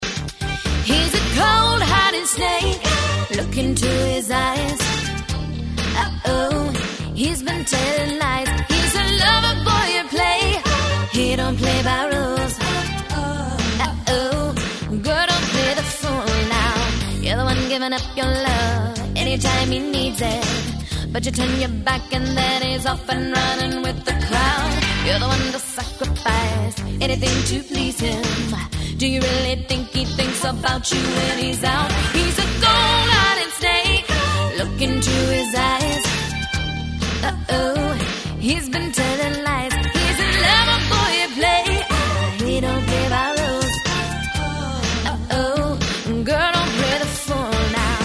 P  O  P     and    R  O  C  K      C  O  V  E  R  S